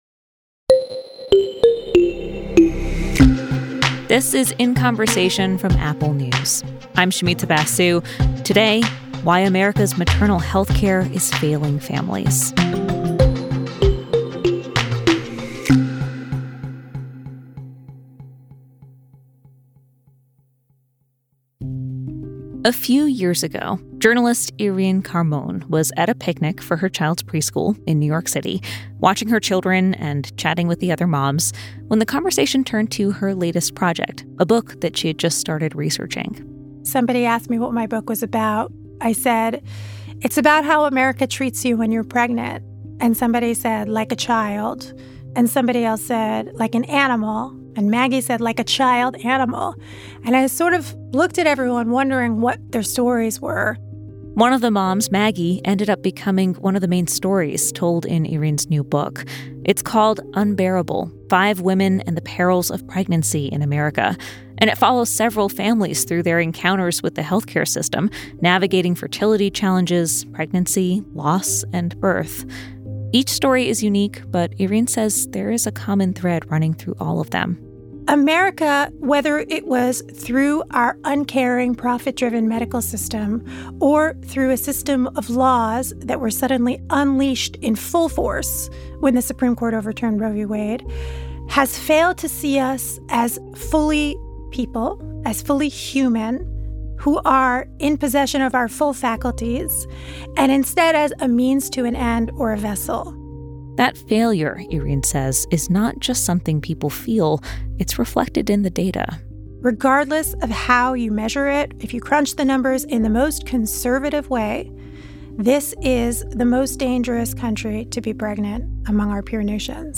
interviews with some of the world’s best journalists and experts about the stories that impact our lives